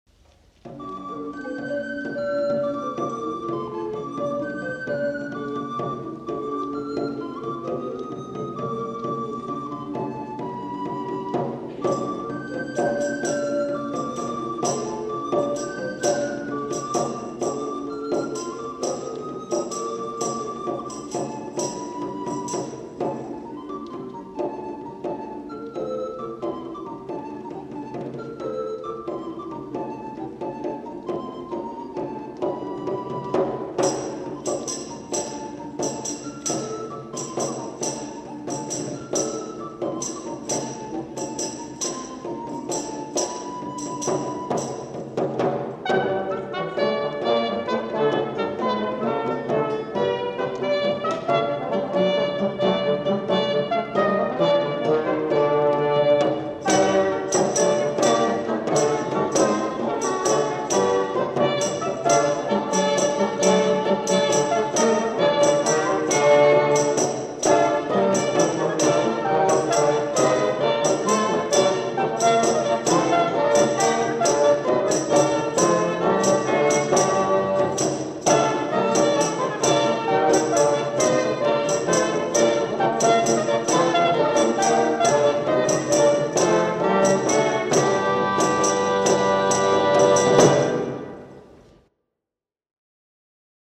Anthony Holborne was a composer and performer of instrumental dances, and lived in the 2nd half of the 16th century.
| Instrumental Ensemble, 'Nowell' 1978